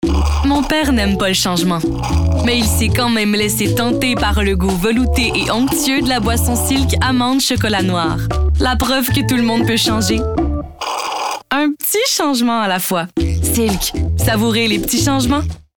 Démos voix